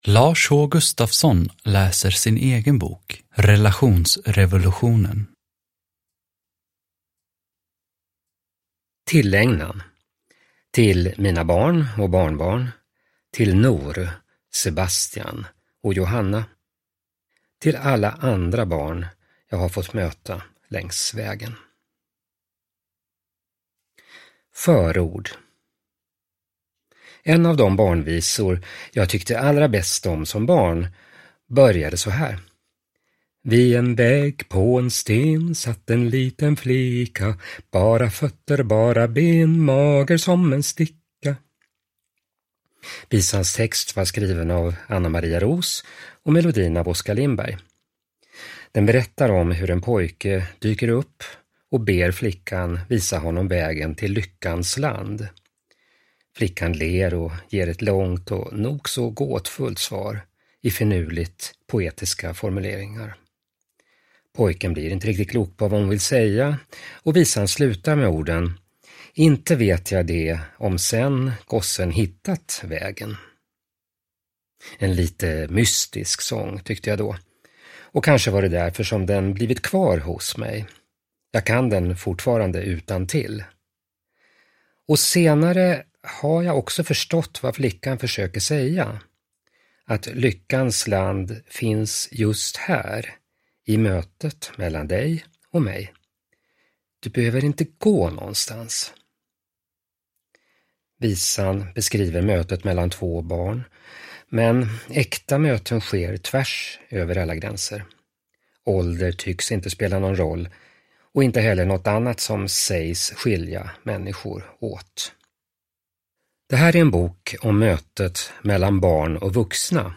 Relationsrevolutionen : om mötet mellan barn och vuxna – Ljudbok